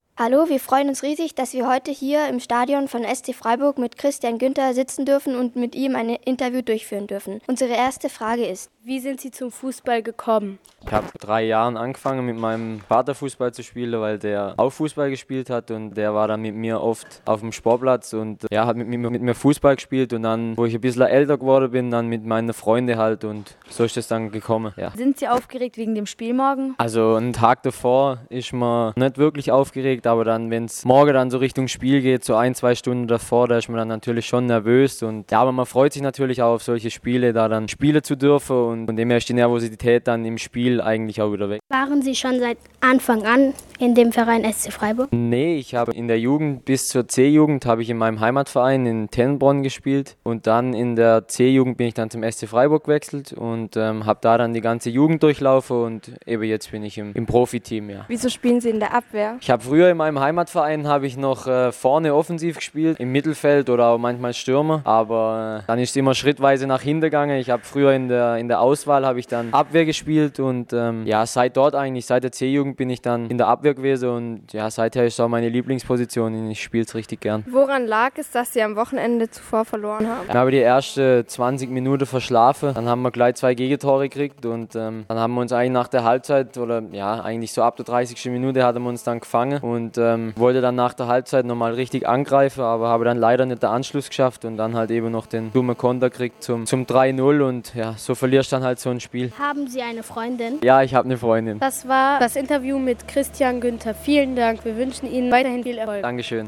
Christian Günter (SC Freiburg) im Interview